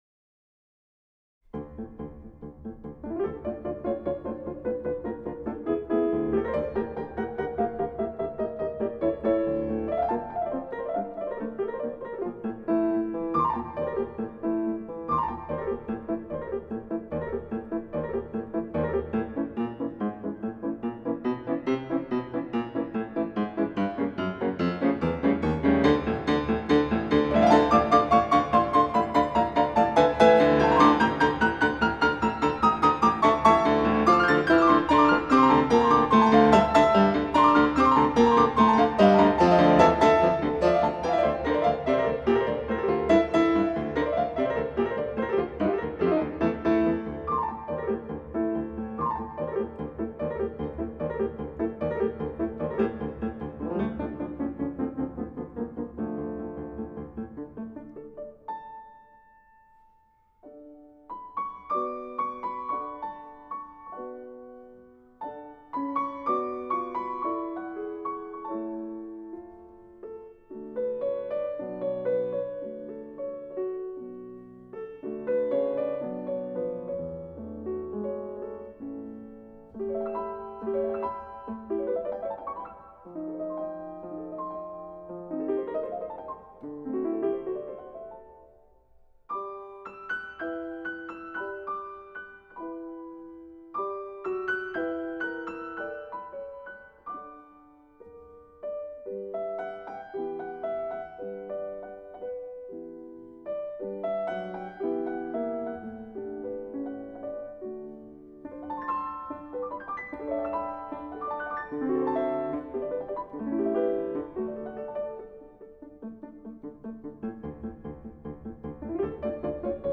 Inhalt Klaviermelodien, die ins Ohr gehen
Schlagworte Beethoven • Debussy • Hörbuch; Literaturlesung • Kinder/Jugendliche: Sachbuch: Musik • Klassische Musik • Klavier • Komponisten • Mozart